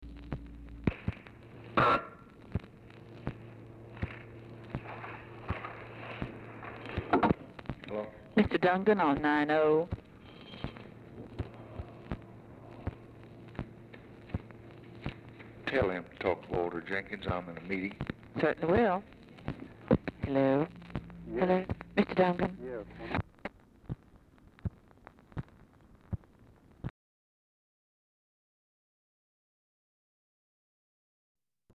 Telephone conversation # 3918, sound recording, LBJ and RALPH DUNGAN, 6/26/1964, time unknown | Discover LBJ
Format Dictation belt
Other Speaker(s) TELEPHONE OPERATOR